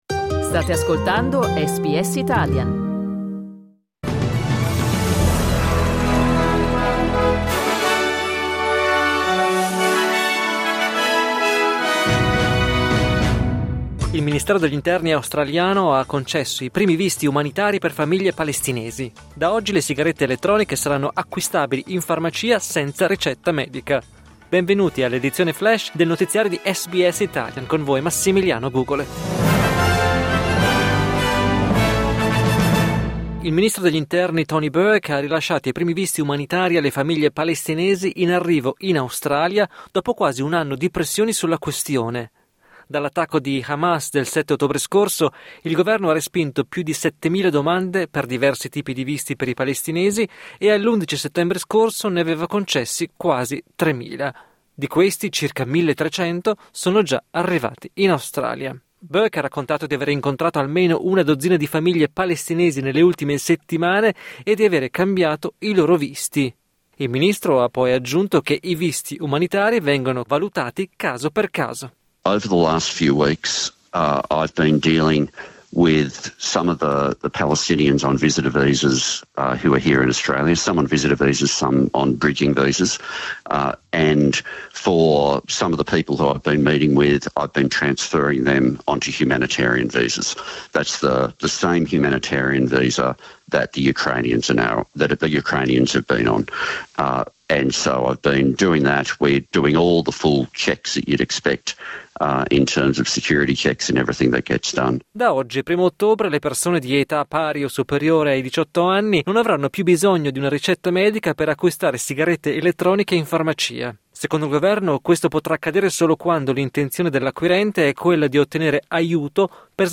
L’aggiornamento delle notizie di SBS Italian.